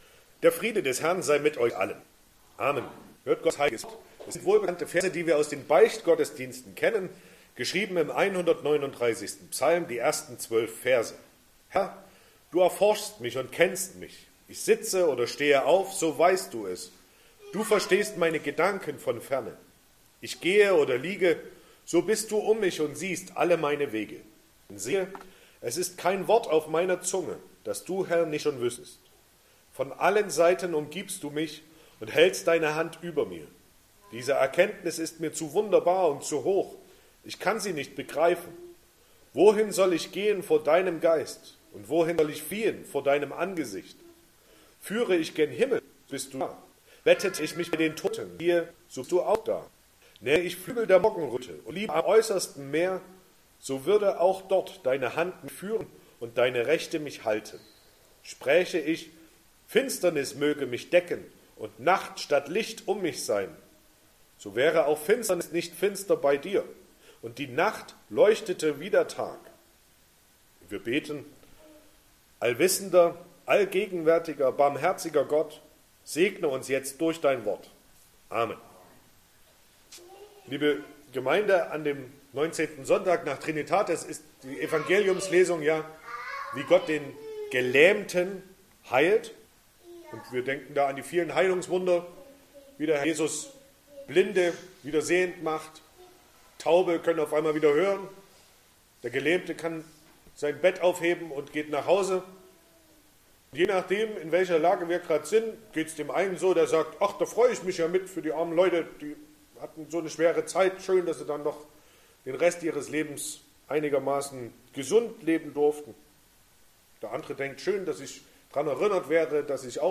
Sonntag nach Trinitatis Passage: Psalm 139, 1-12 Verkündigungsart: Predigt « Erntedankfest 2021 20.